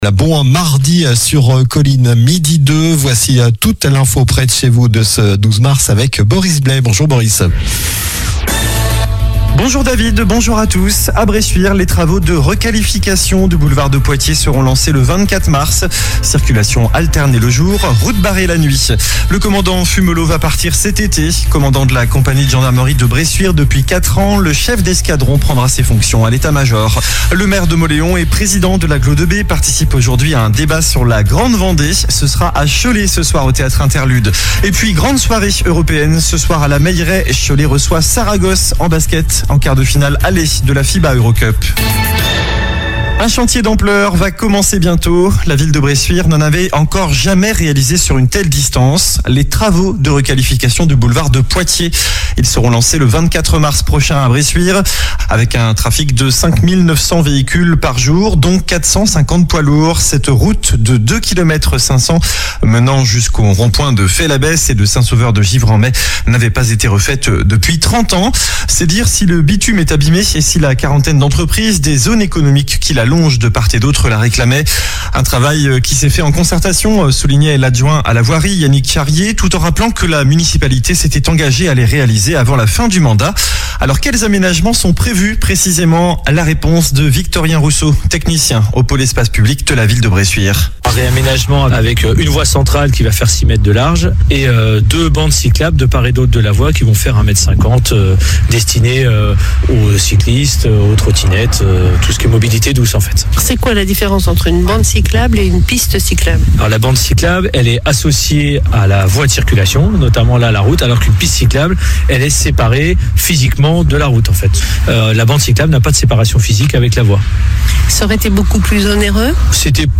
Journal du mercredi 12 mars (midi)